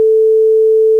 La440Hz=m69.wav